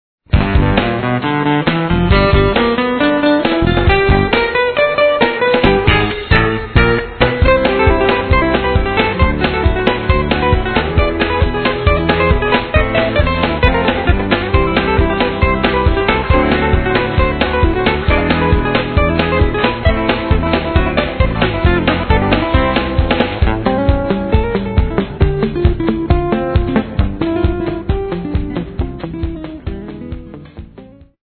guitare cordes nylon
Percussions, basse, guitares (électrique & acoustique)